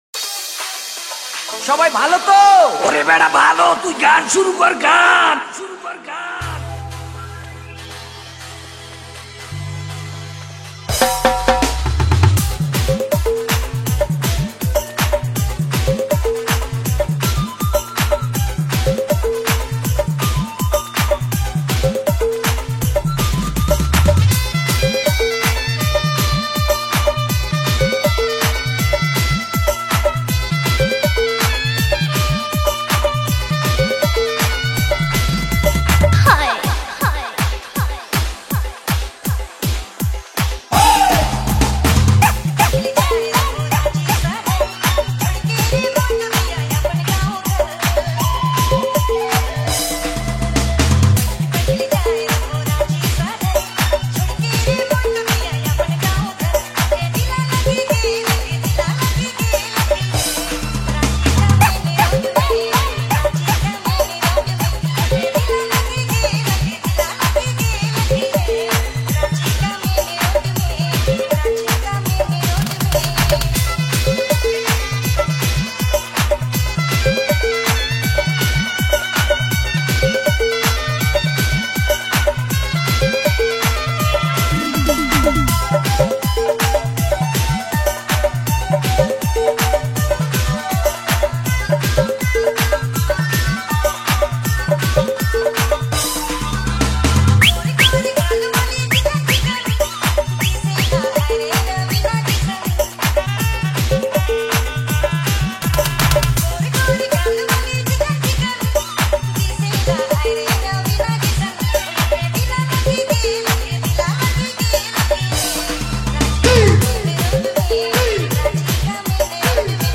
New Nagpuri Dj Song 2025